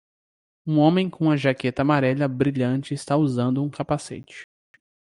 Read more helmet (protective head covering) Frequency C1 Pronounced as (IPA) /ka.paˈse.t͡ʃi/ Etymology From Latin caput (“head”), from Proto-Indo-European *kauput-.